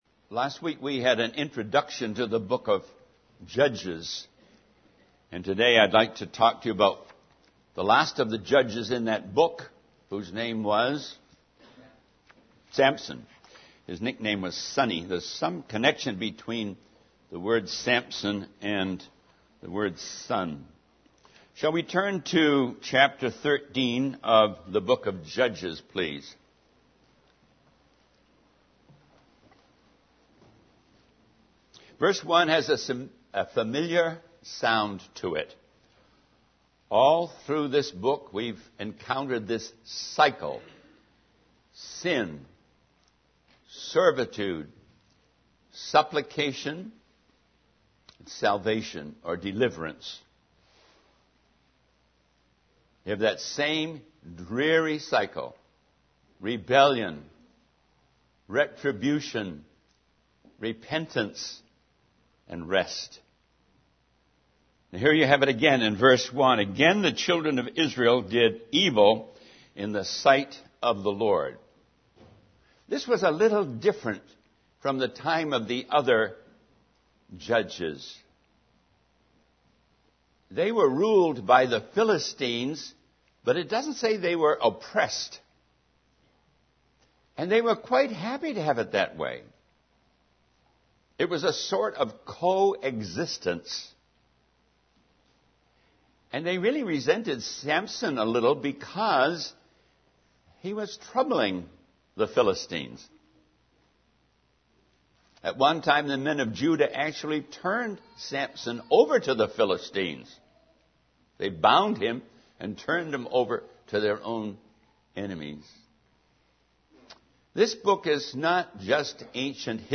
In this sermon, the preacher discusses the cycle of sin and redemption that is seen throughout the book of Judges. He emphasizes that sin enslaves us and leads to a cycle of rebellion, retribution, repentance, and rest.